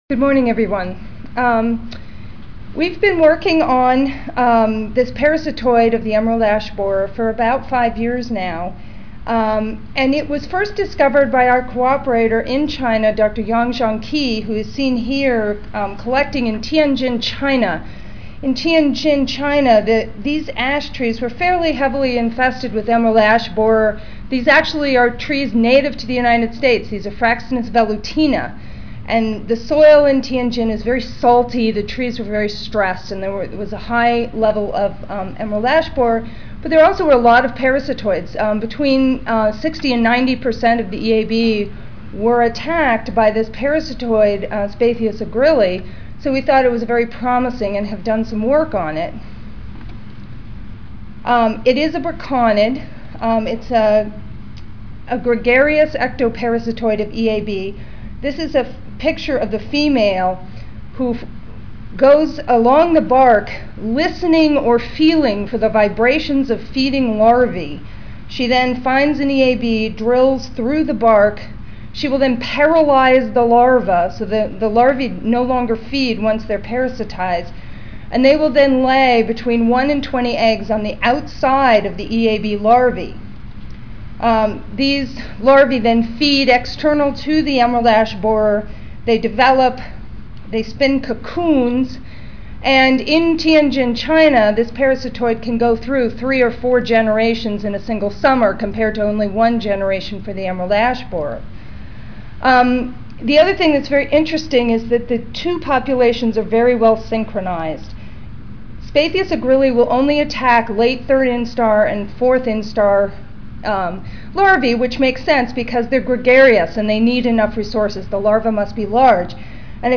Room A12, First Floor (Reno-Sparks Convention Center)
Ten Minute Paper (TMP) Oral